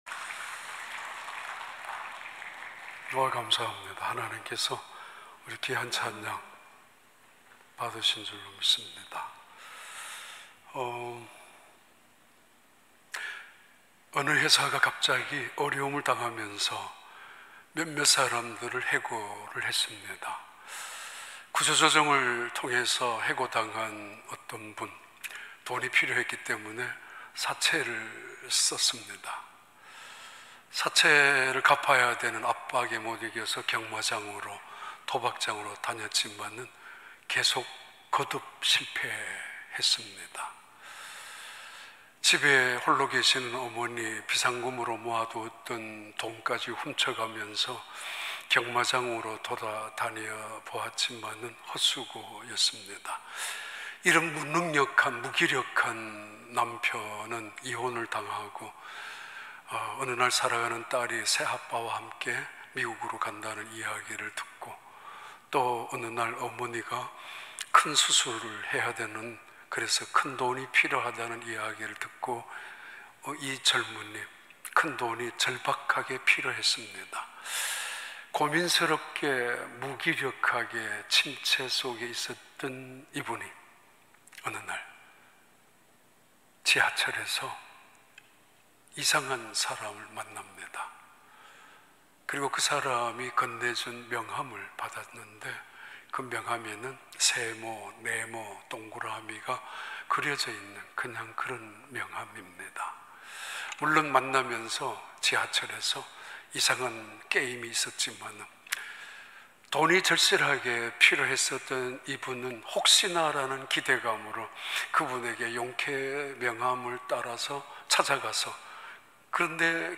2021년 10월 24일 주일 3부 예배